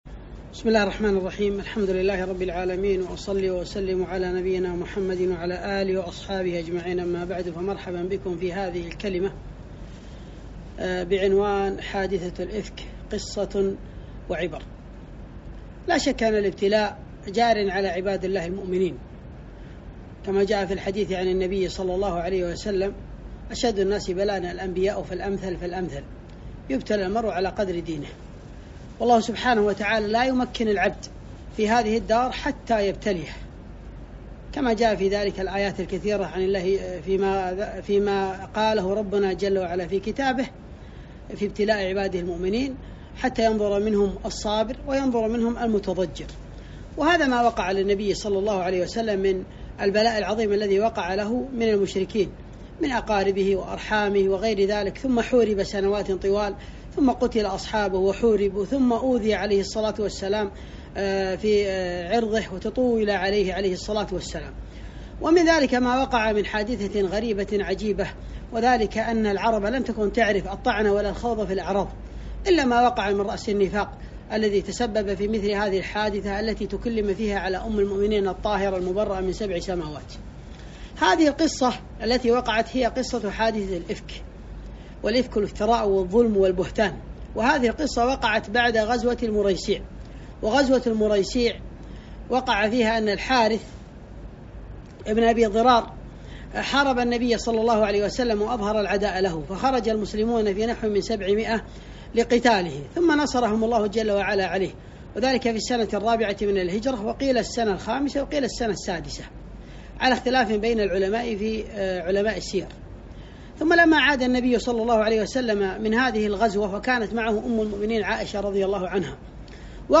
محاضرة - حادثة الإفك قصة وعبر